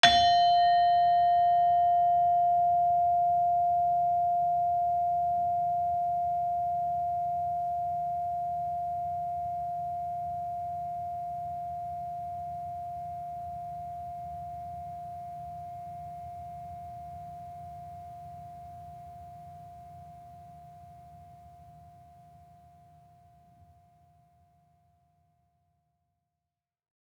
Gender-4-F4-f.wav